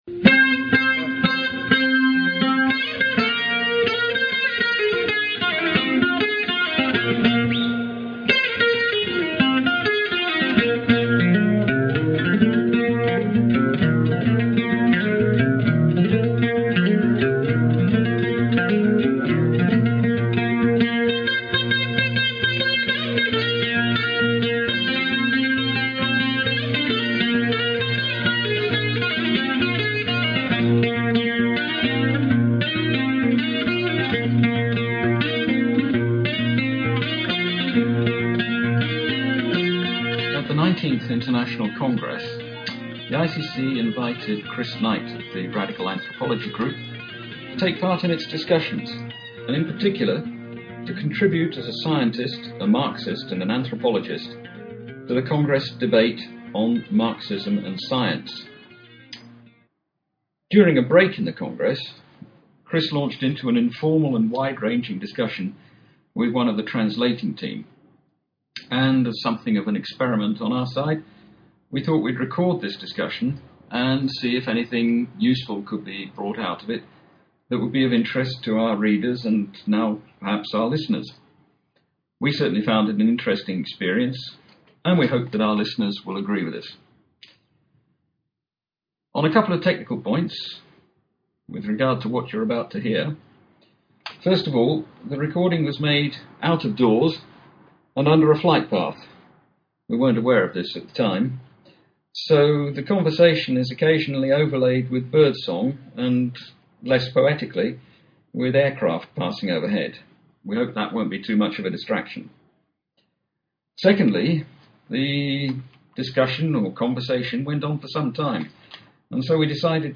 Wait for the music to stop!